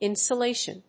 音節in・so・la・tion 発音記号・読み方
/ìnsoʊléɪʃən(米国英語)/
insolation.mp3